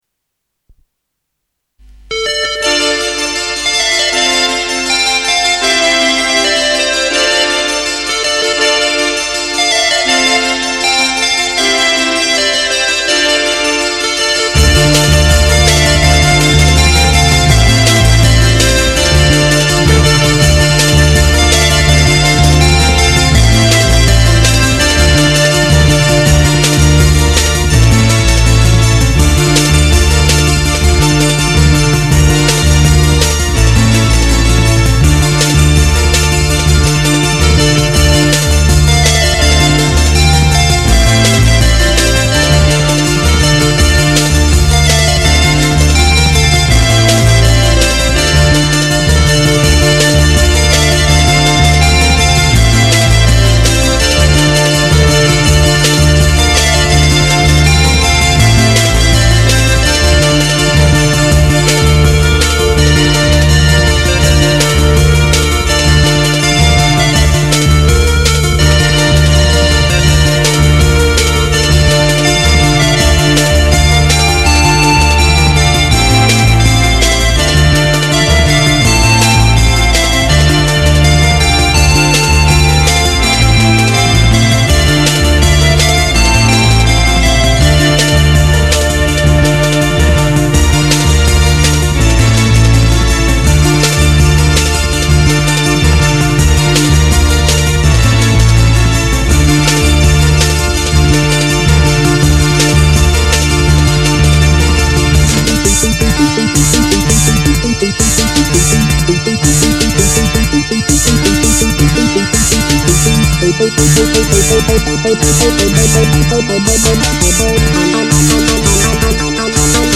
Recorded in MIDI or MDD technloge.